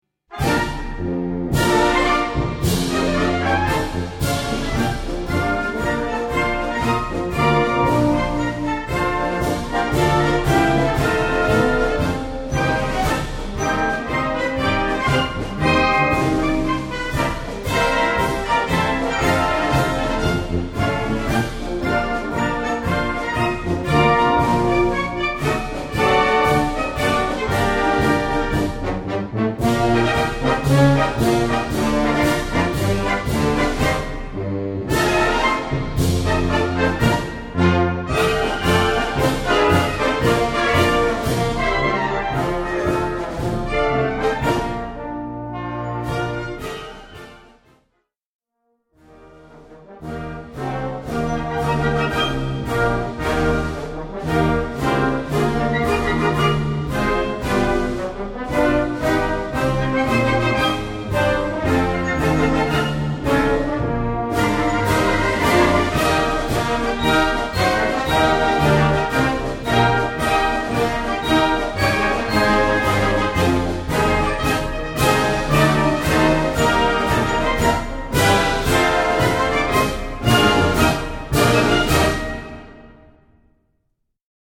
Gattung: Marsch
3:10 Minuten Besetzung: Blasorchester PDF